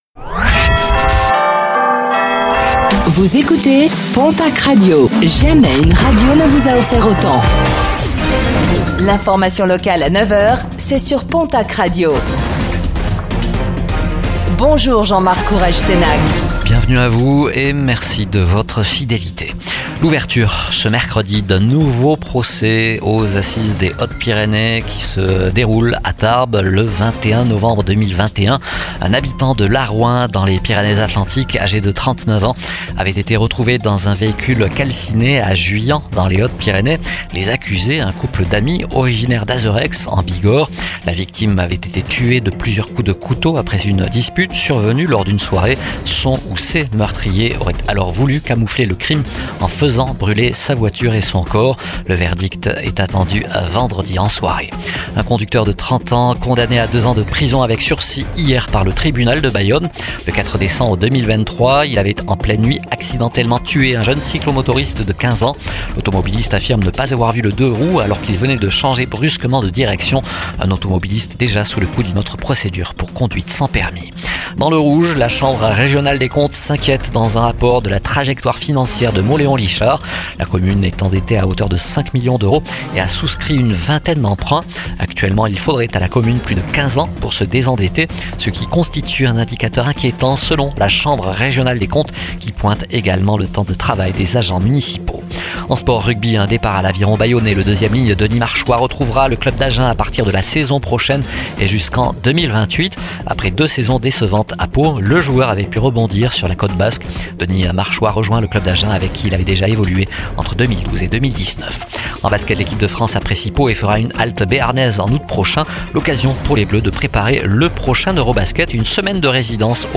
Réécoutez le flash d'information locale de ce mercredi 12 mars 2025